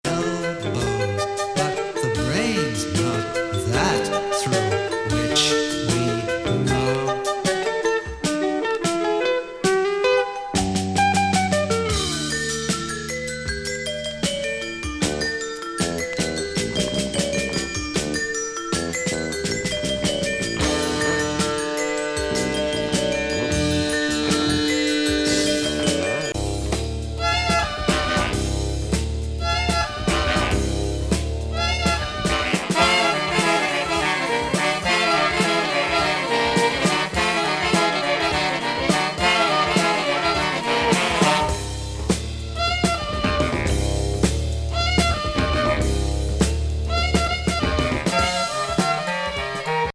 ・プロッグな変ジャズ・アルバムに仕上がっております！